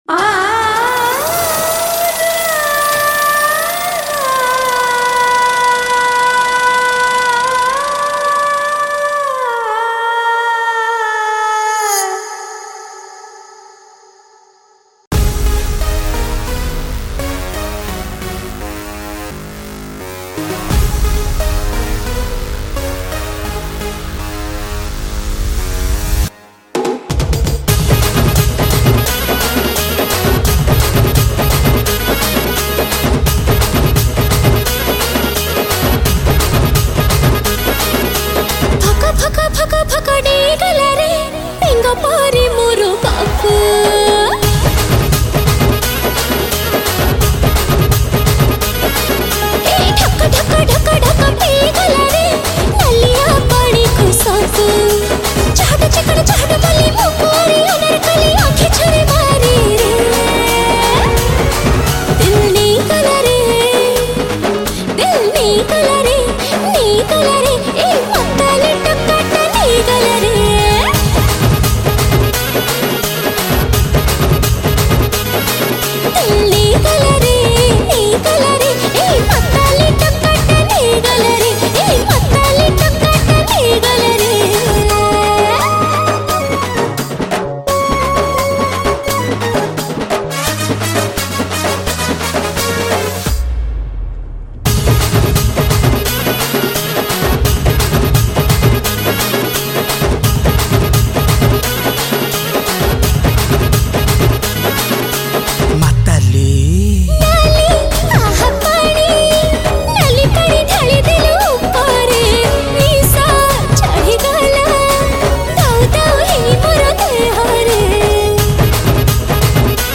Keyboard Programing
Rhythm Programmed
Acoustic Music